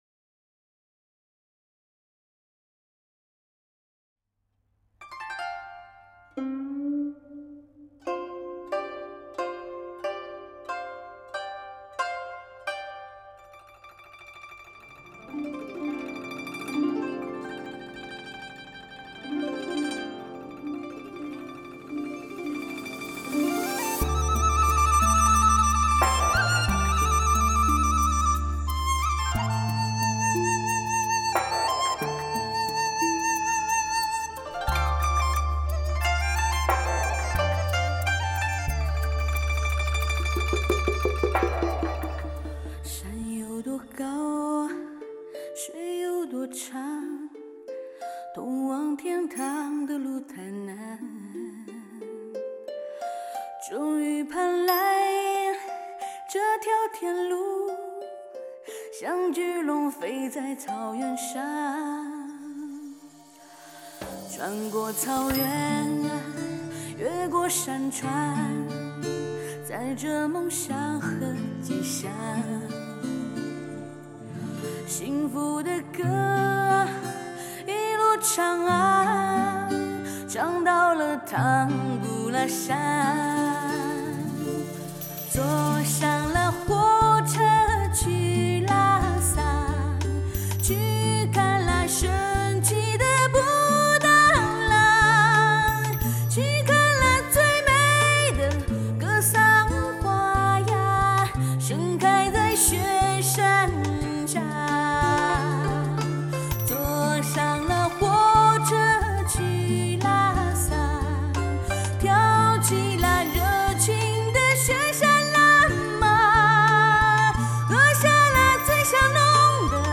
藏风特色配器，注入现代音乐新元素
淡淡的，柔柔的，天籁般的歌声从天边漂来……